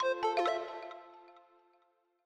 Longhorn 8 - Hardware Insert.wav